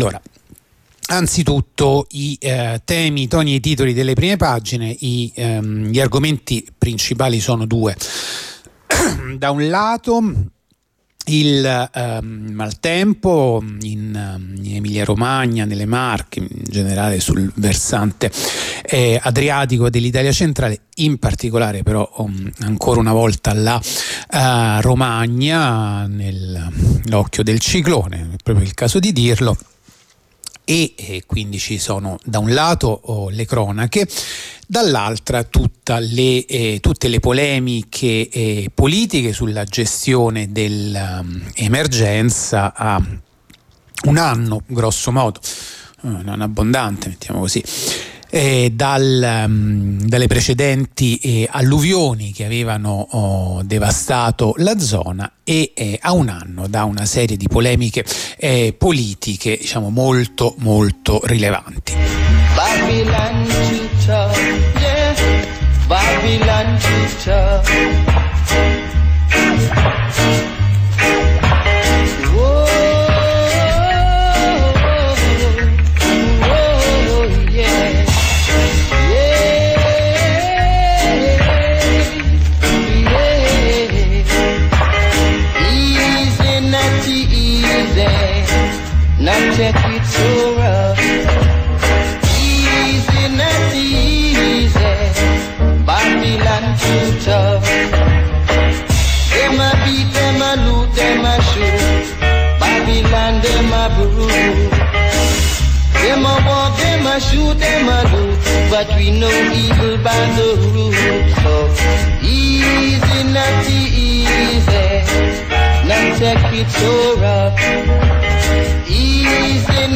La rassegna stampa di radio onda rossa andata in onda venerdì 20 settembre 2024